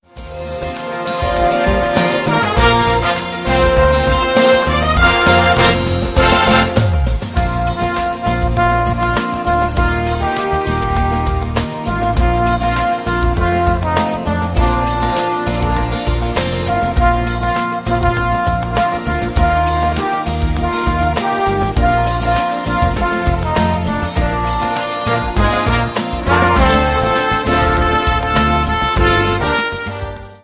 • Sachgebiet: Instrumental